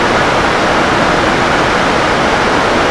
wind_cockpit.wav